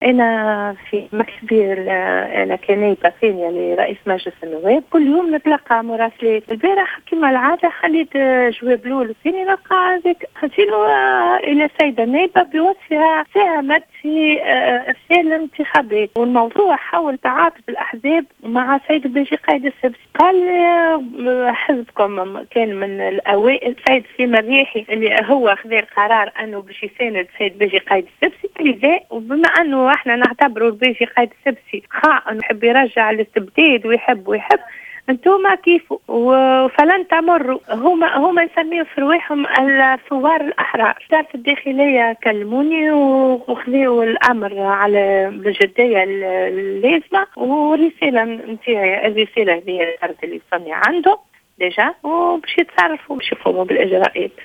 أكدت النائب الثاني لرئيس مجلس الشعب،فوزية بن فضة في تصريح ل"جوهرة أف أم" تلقيها رسالة تهديد.